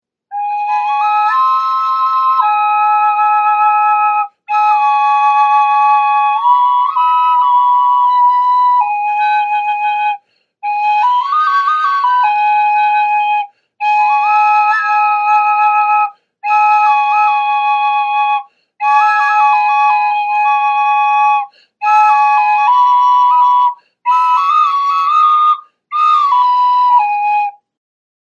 mayan aztec flute traditional pentatonic sound healing
Small Ceremonial Mayan drone flute
Ceramic drone flute
The Mayan Ceramic Flute is hand tuned to produce a double sound.  This replica ancient instrument has two flute built into one,  you can play one flute at a time or play both using one chamber as a drone or play at the same time.